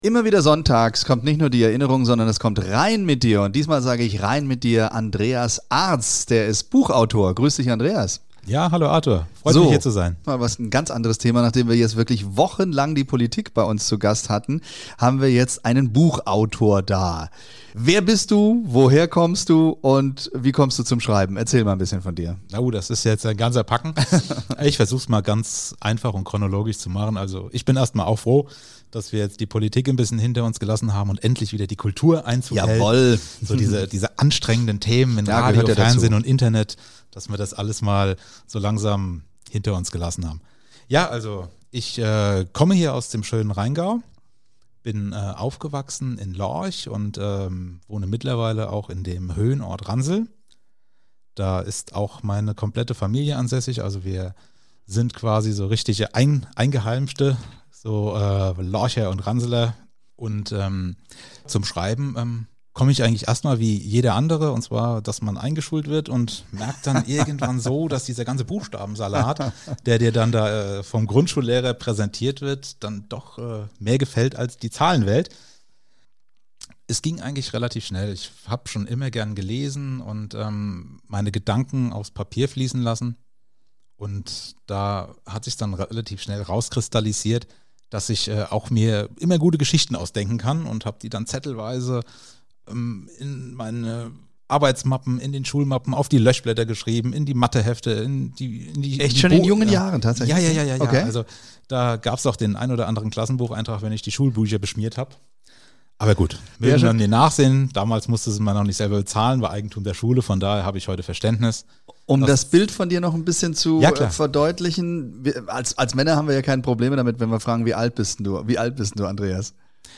Interview und Lesung bei Radio RheinFM